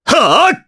Dimael-Vox_Attack2_jp.wav